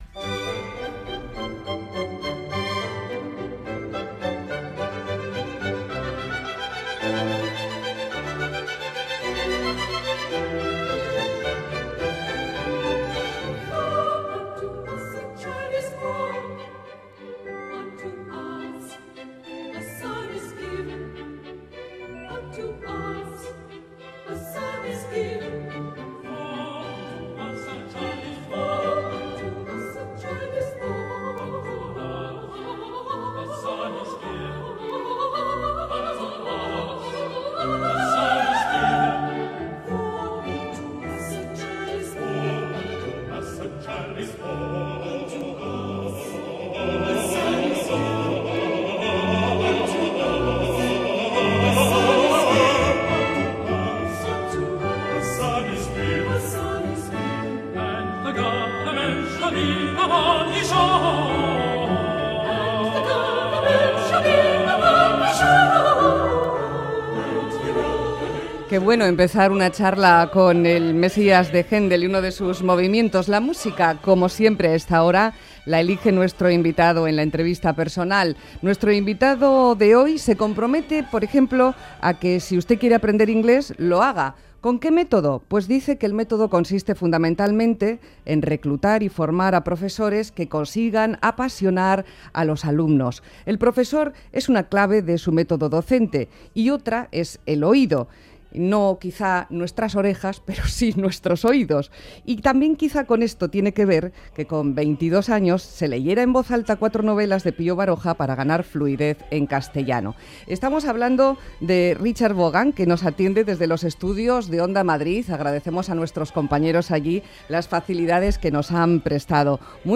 Entrevista personal | Radio Euskadi